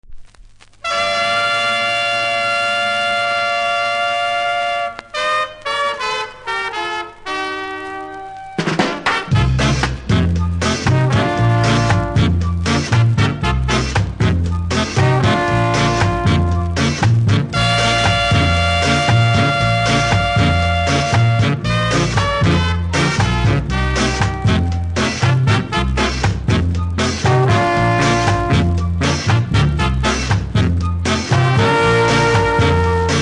出だしの無録音部分にノイズありますがその他は良好です。